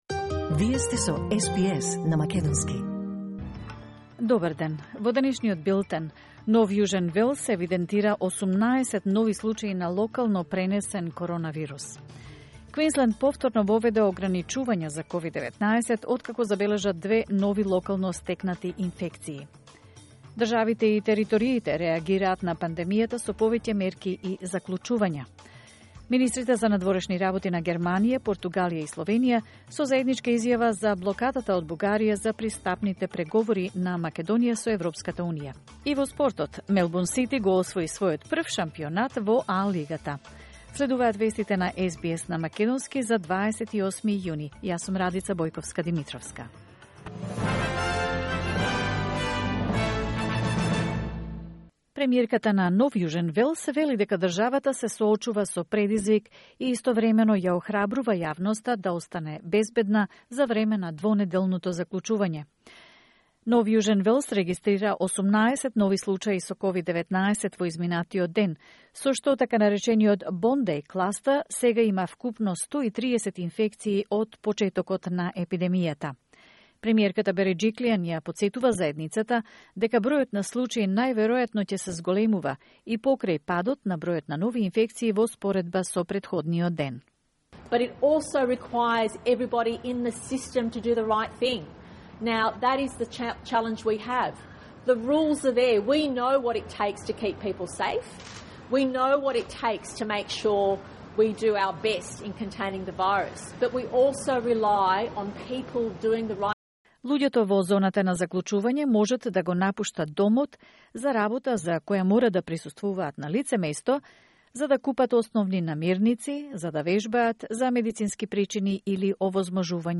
SBS News in Macedonian 28 June 2021